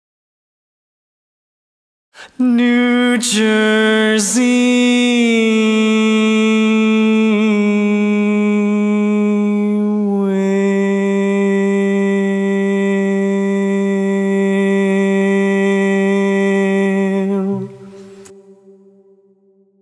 Key written in: C Major
Type: Barbershop
Each recording below is single part only.